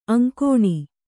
♪ aŋkōṇi